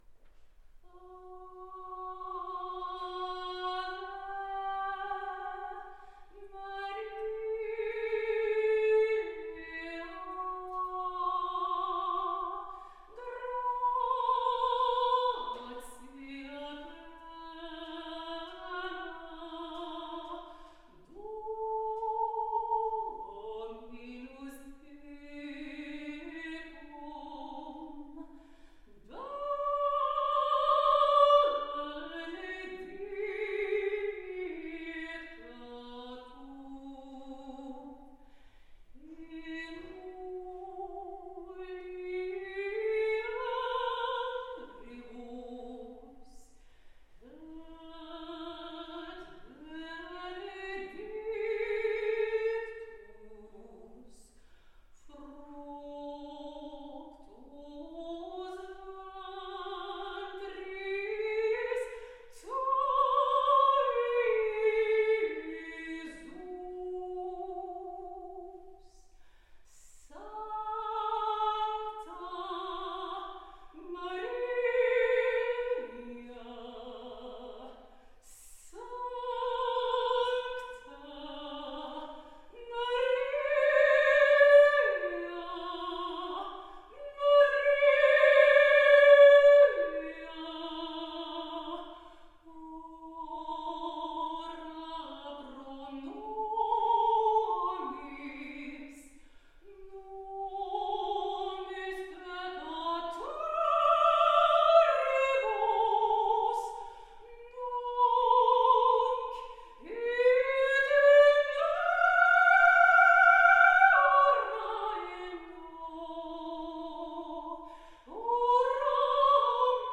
mezzo-soprano
Lecture-Concert « Marie » – Leschaux, France